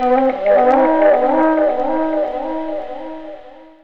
0507L FLUTFX.wav